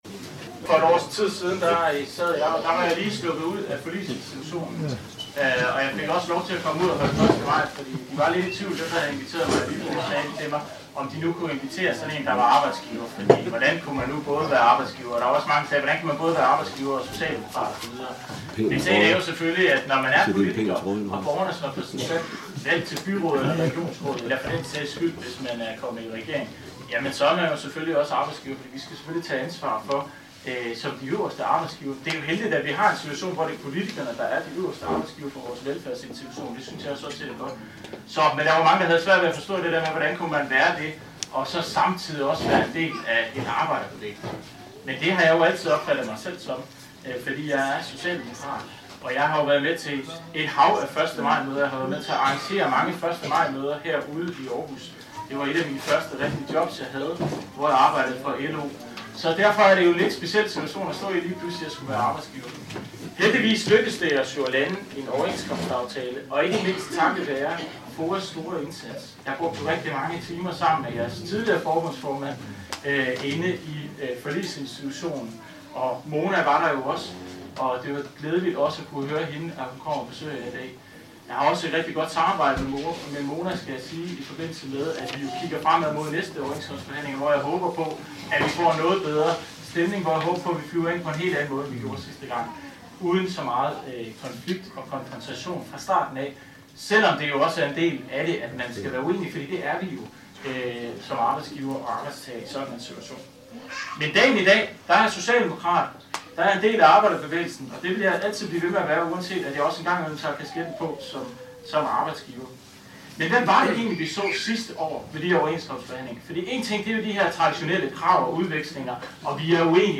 Dagen begyndte hos FOA Århus kl. 08.00 med morgenmad, kaffe, en lille en, røde pølser, fadøl, hygge og taler.
Her ventede musik, hygge, godt vejr og flere talere.